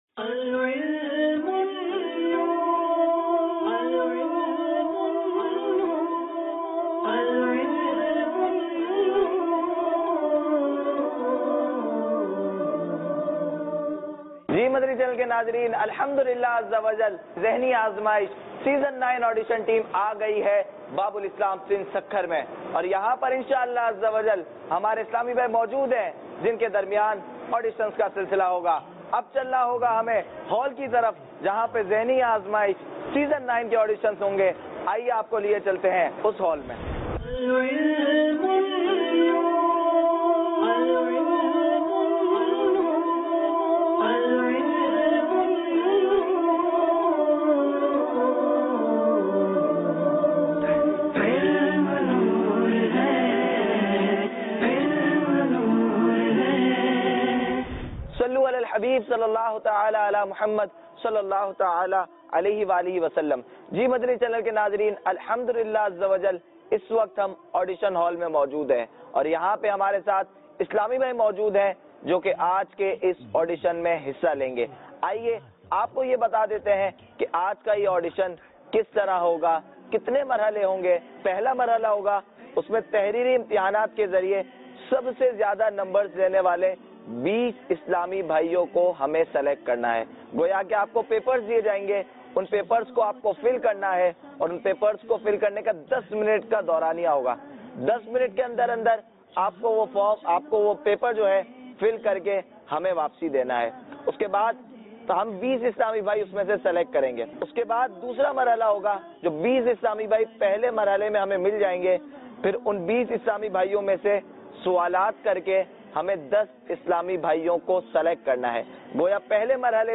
Silsila Zehni Azmaish Season 09 Audition Ep 16 - Sukkur Dec 27, 2017 MP3 MP4 MP3 Share Silsila Zehni Azmaish Season 9 Audition k silsiley me Sindh k shehar Sukkur me Madani Channel ki team ne jamia tul Madina k talaba ka audition kia.